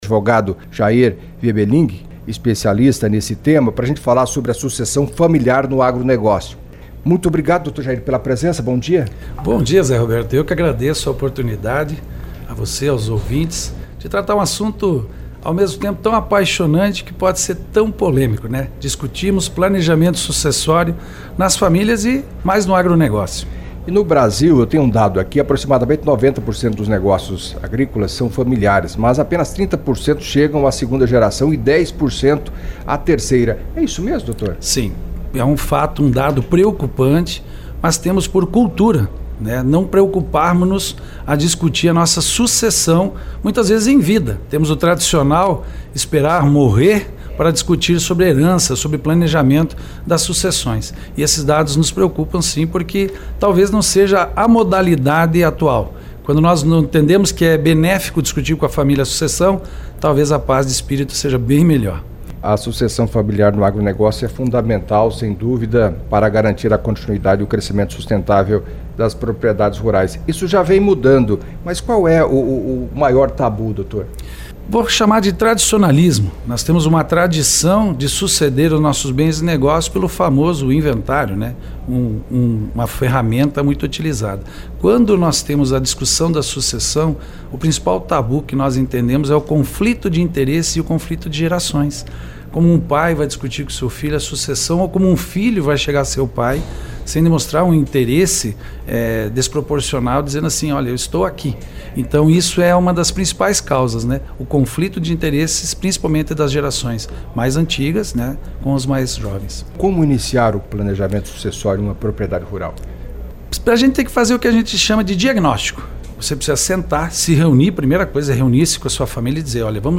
Em entrevista à CBN Cascavel nesta segunda-feira (10), no estúdio da emissora no Parque Tecnológico Coopavel, o advogado